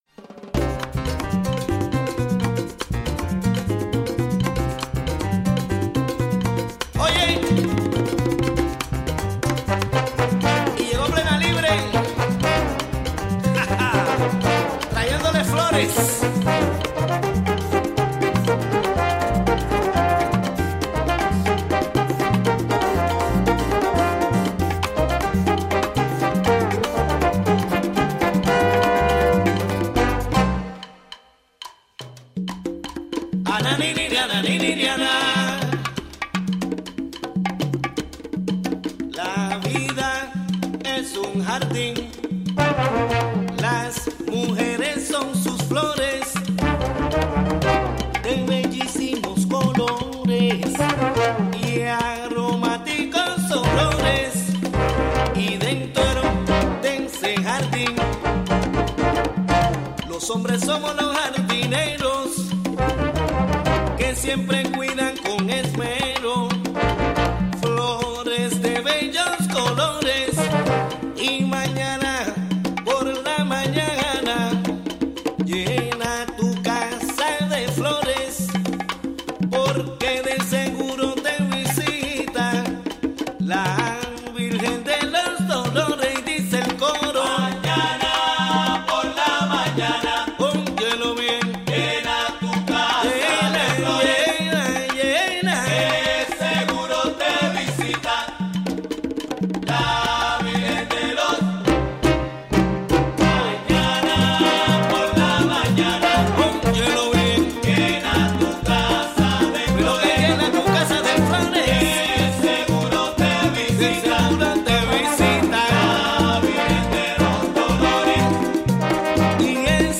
A weekly Spanish language radio news show featuring interviews, commentary, calendar of events and music.